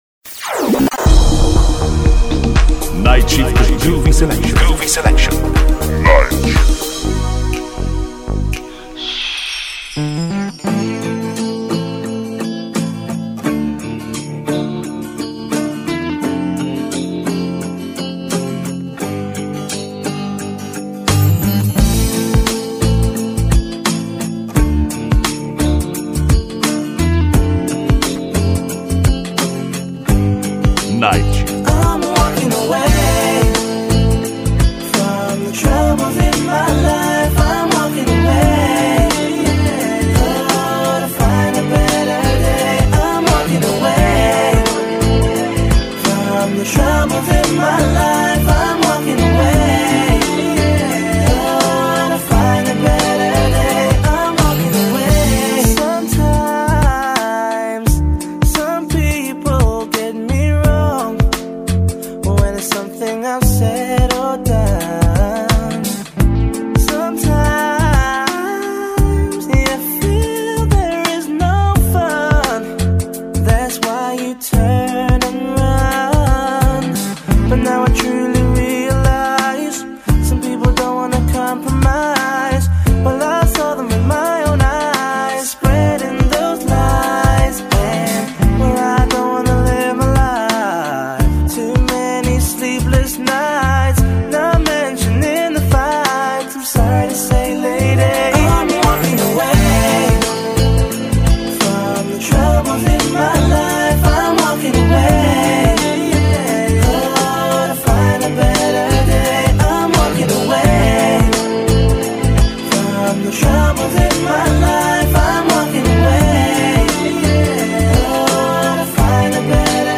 Groovy Selection